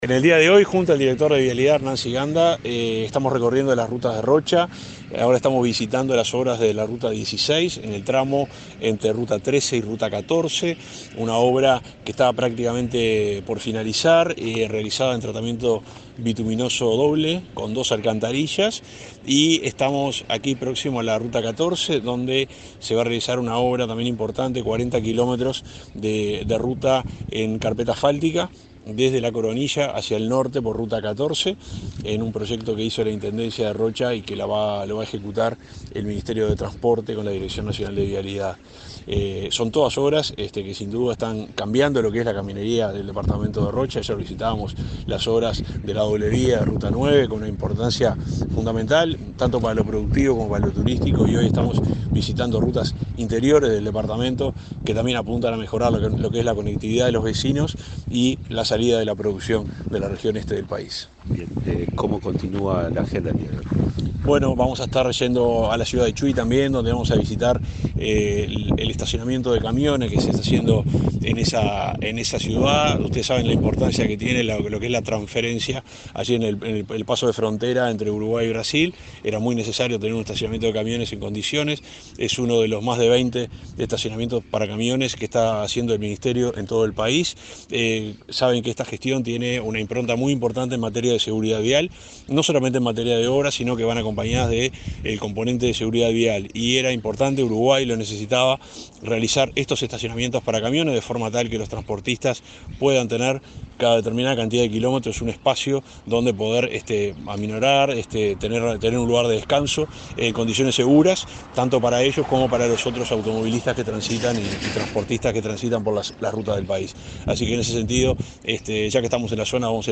Entrevista al ministro interino de Transporte, Juan José Olaizola, y al director de Vialidad, Hernán Ciganda
En diálogo con Comunicación Presidencial, el ministro interino de Transporte y Obras Públicas, Juan José Olaizola, y el director nacional de Vialidad, Hernán Ciganda, informaron, durante una recorrida por Rocha, acerca de las obras en la ruta n.° 14 y el estacionamiento de camiones en la localidad de Chuy.